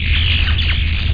_RAILGUN.mp3